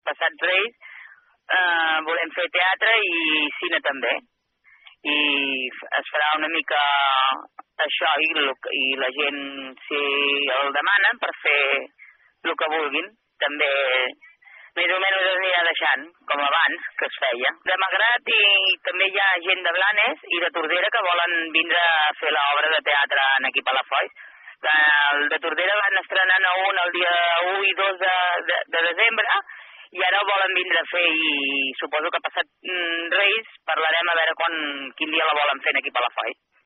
Ho explica Montserrat Rovira, regidora de Cultura de l’Ajuntament de Palafolls.